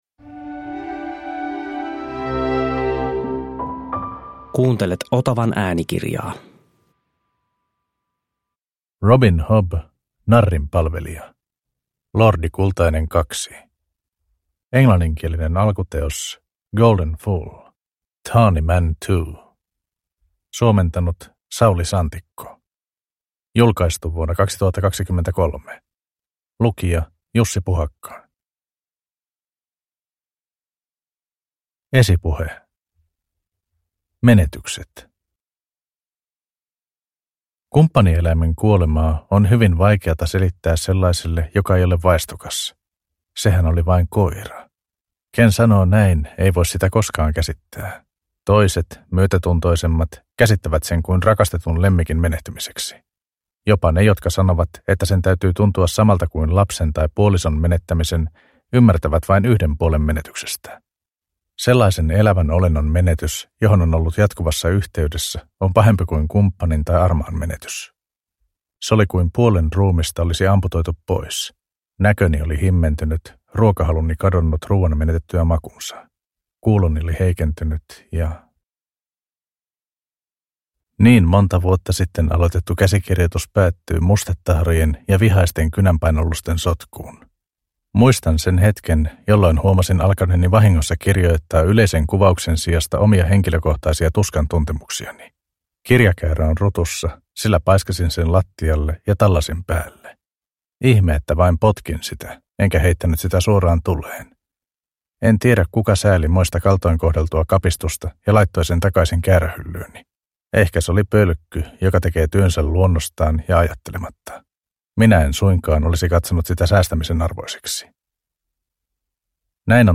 Narrin palvelija – Ljudbok